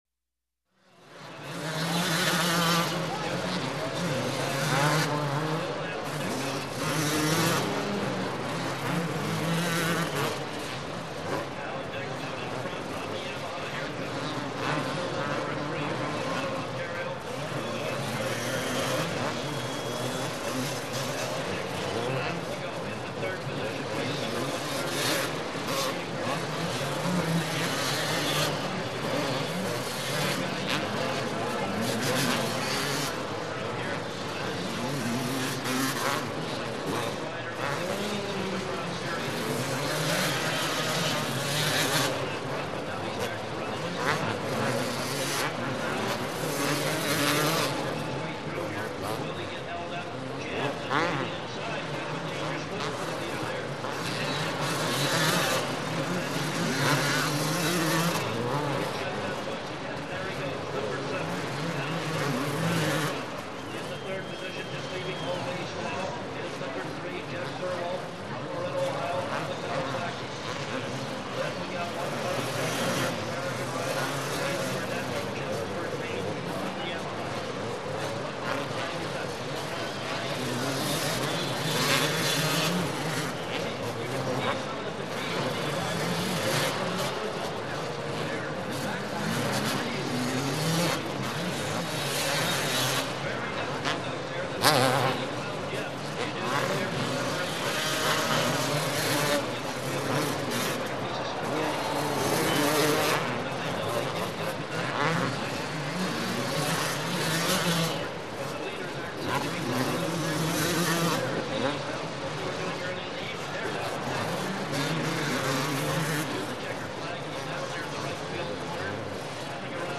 Звуки мотокросса
Звук мотокросса рев гоночных мотоциклов езда по трассе шум толпы и голос диктора